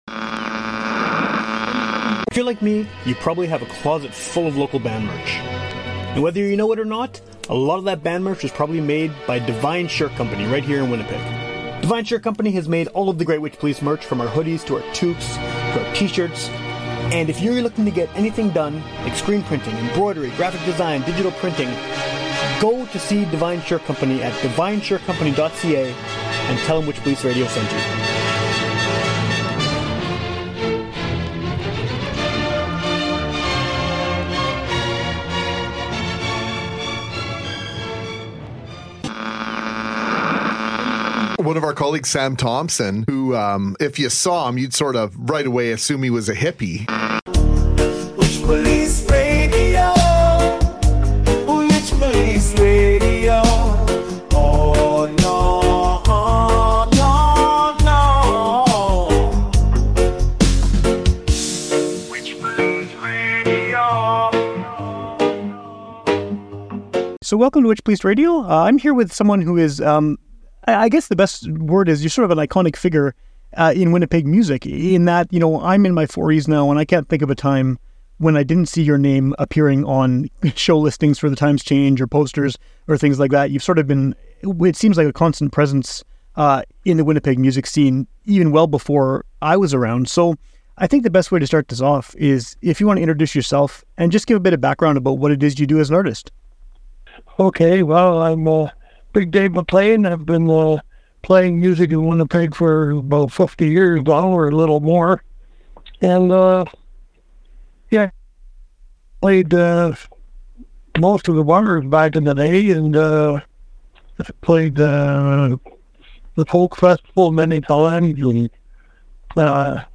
Thanks for listening.*You can tell it's a phone call too, but I did what I could to improve the audio quality so you don't lose any of the conversation.